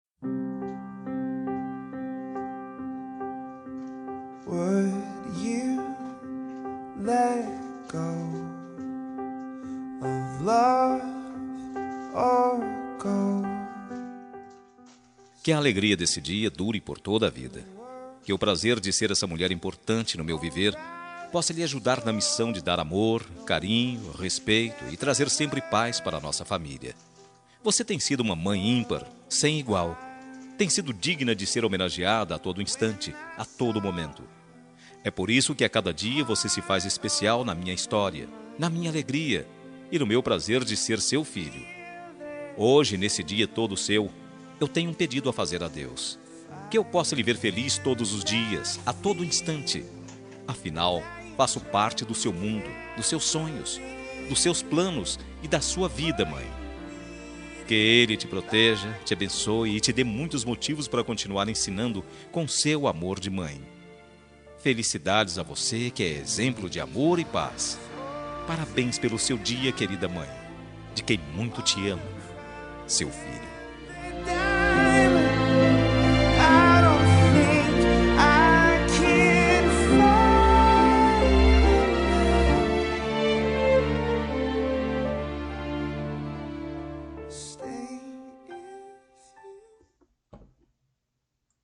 Dia das Mães – Para minha Mãe – Voz Masculina – Cód: 6518